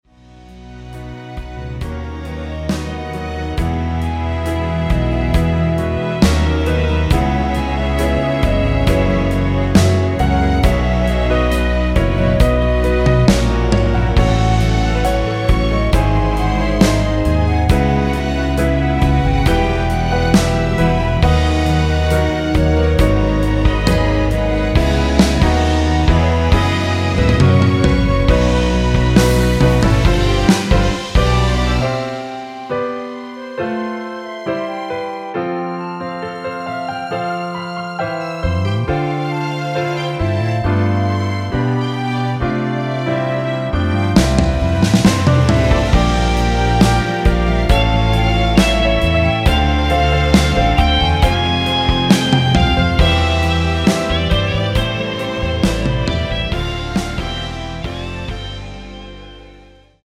원키(1절+후렴)으로 편곡된 멜로디 포함된 MR입니다.(미리듣기및 가사 참조)
앞부분30초, 뒷부분30초씩 편집해서 올려 드리고 있습니다.